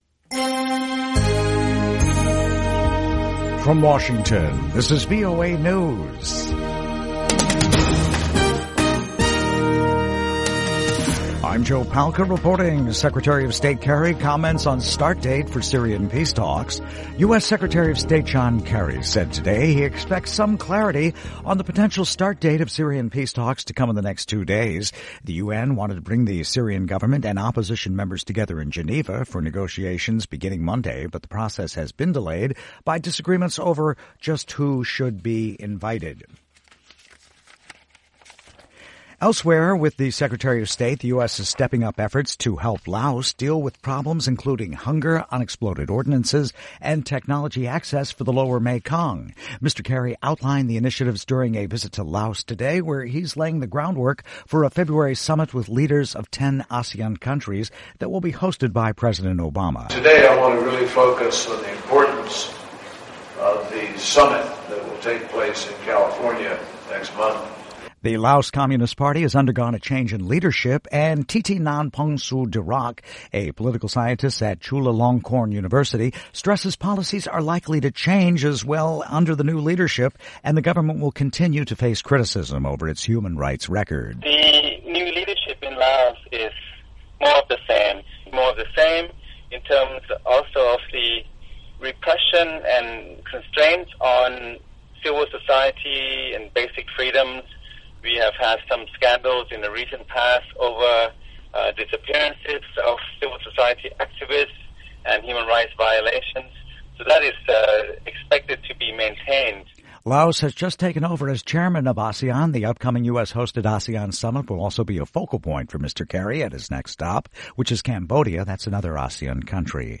VOA English Newscast 1300 UTC January 25, 2016